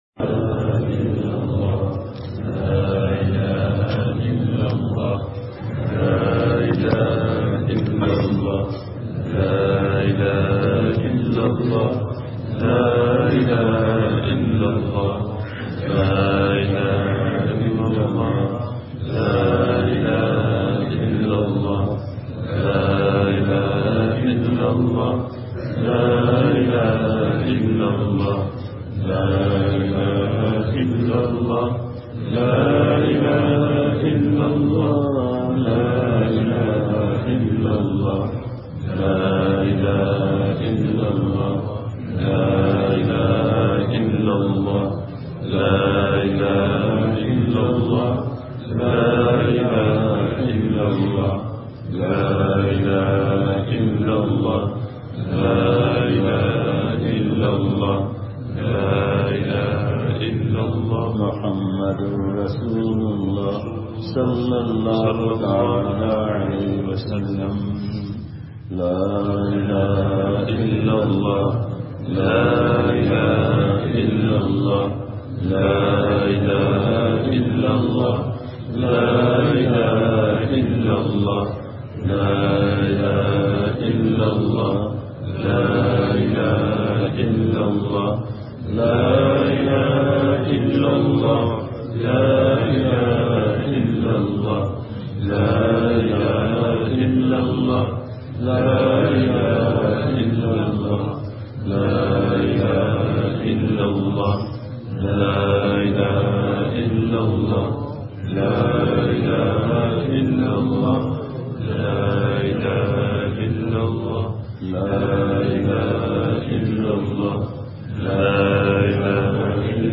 بیان
بعد فجر صبح ساڑھے پانچ بجے بیان مدرسہ سے متصل مسجد میں حضرت والا کا پرنور بیان ہوا۔